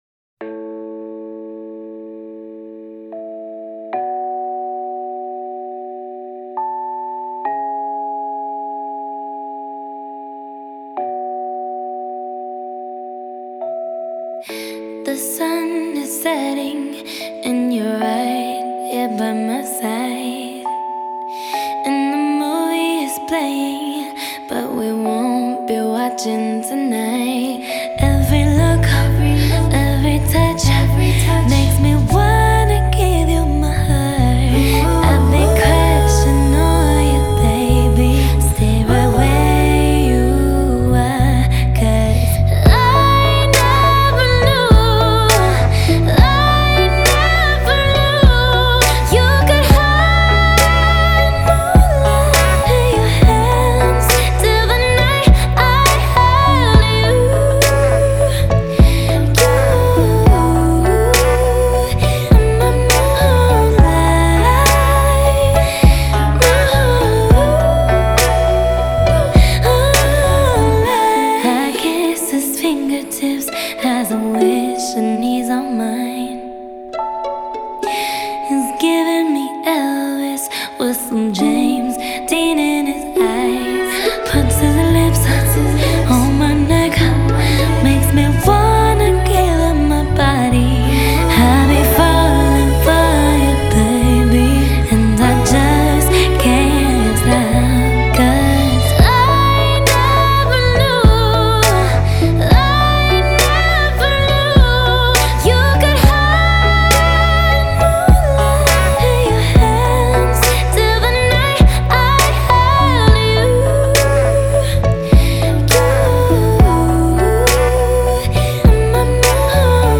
Pop, R&B, Dance-Pop, Electropop